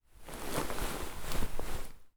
Cloth, Rustle